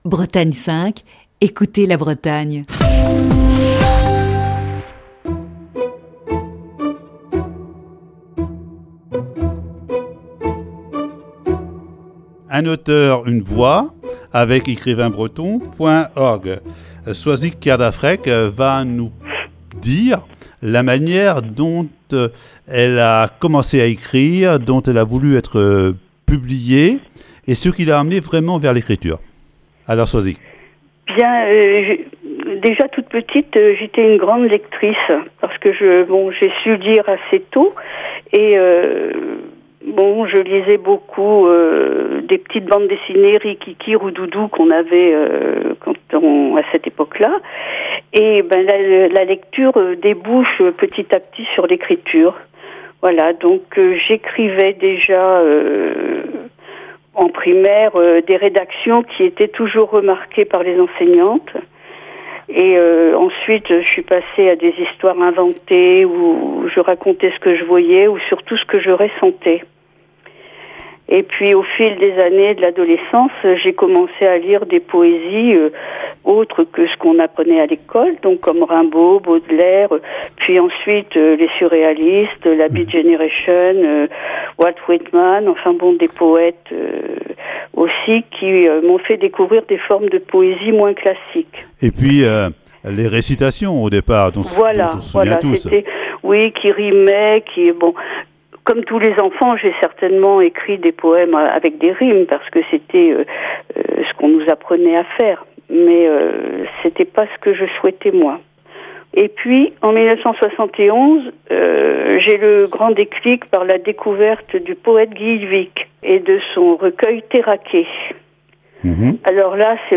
Aujourd'hui, troisième partie de cet entretien.